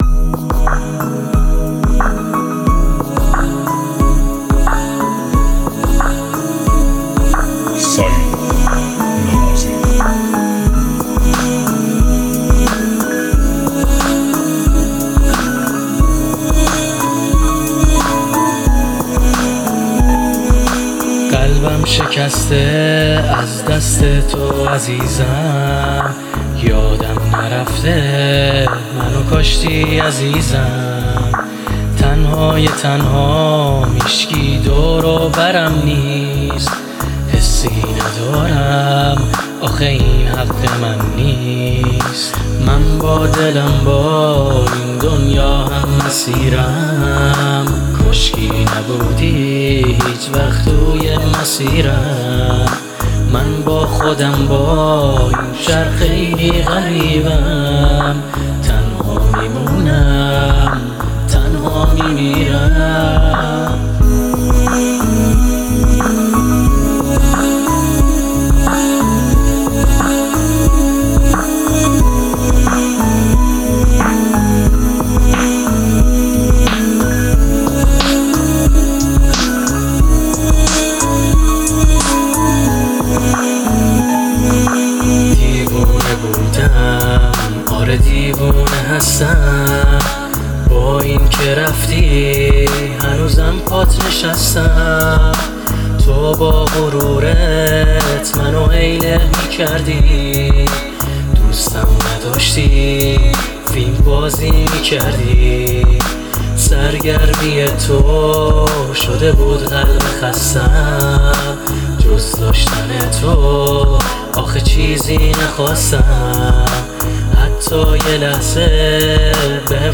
آهنگ بصورت کلی خوب بود، صدای خواننده باید واضح تر باشه.